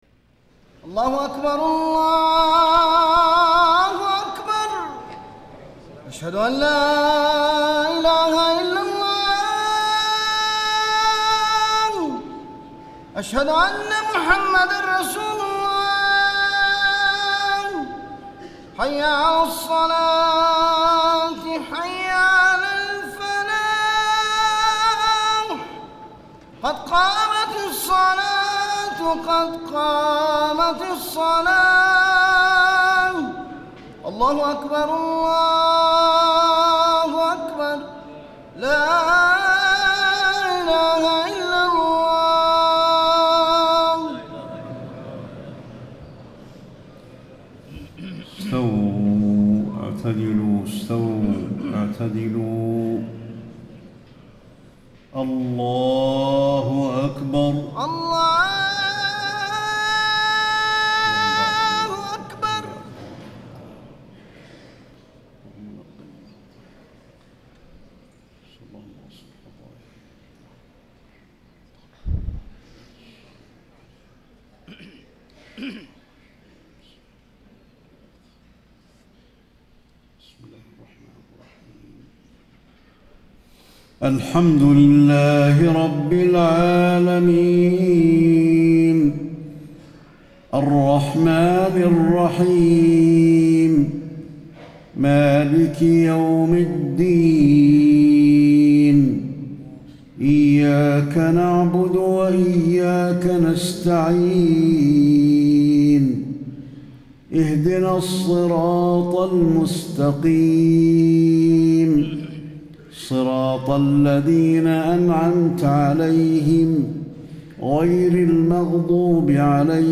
صلاة العشاء 2-5-1435 سورتي التكوير و الشمس > 1435 🕌 > الفروض - تلاوات الحرمين